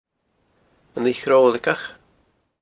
Locally, the name was An Lighe Chròlaigeach, pronounced un lee chroal-ik-uch